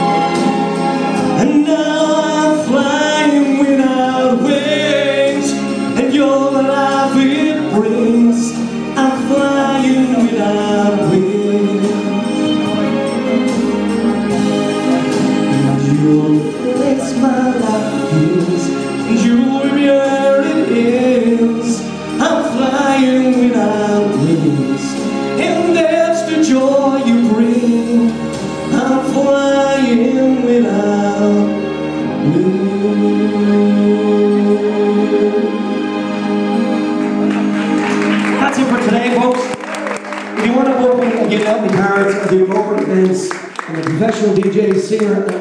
SMEcommunity tweet up...In Huntington Castle.